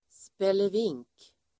Uttal: [spe:lev'ing:k]